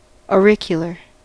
Ääntäminen
US : IPA : [ɔ.ˈɹɪk.jə.lɚ]